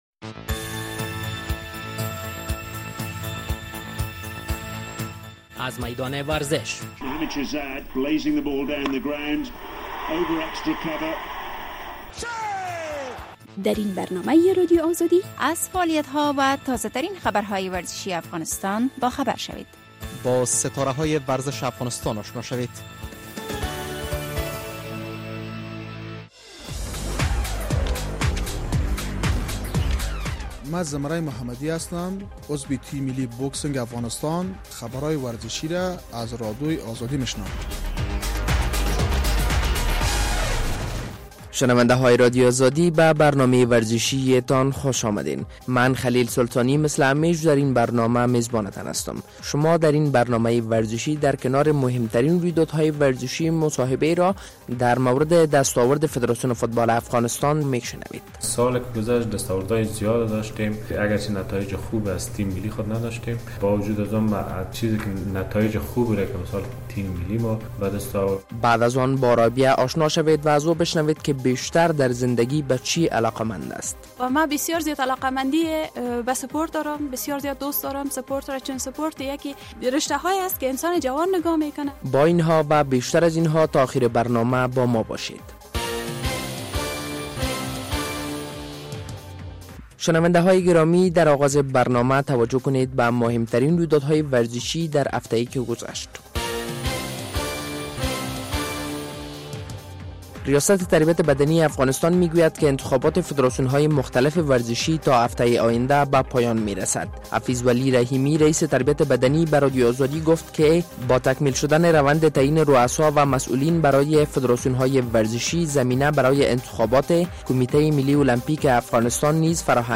در برنامه این هفته در کنار مهمترین خبرهای ورزشی مصاحبه در مورد کارکرد و دستاورد فدراسیون فوتبال افغانستان را می‌شنوید.